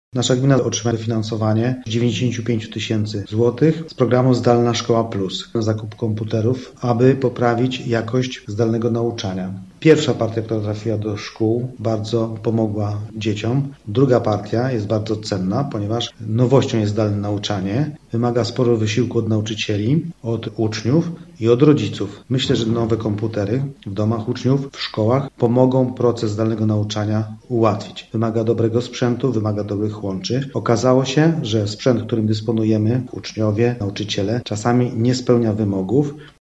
Burmistrz Rudnika Waldemar Grochowski przyznaje, że ułatwi to zdalne nauczanie, które pokazało spore braki w zakresie odpowiedniego do tego celu sprzętu: